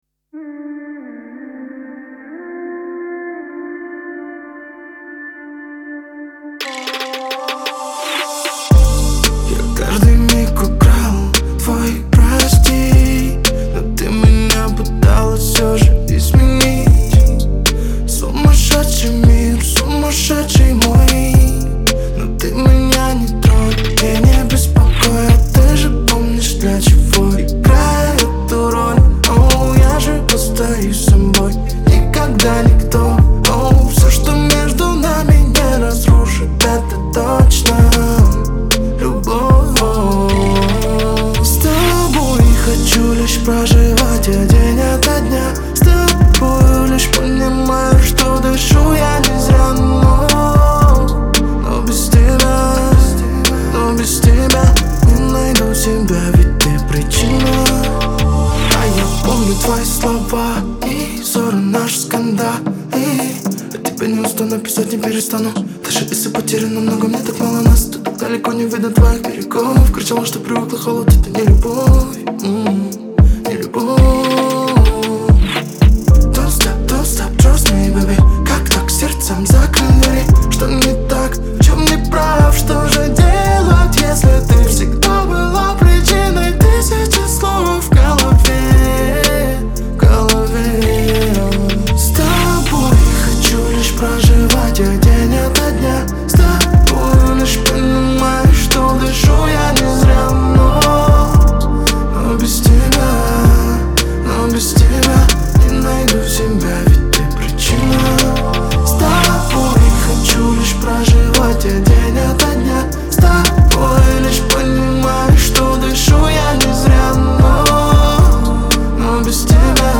Трек размещён в разделе Русские песни / Поп / 2022.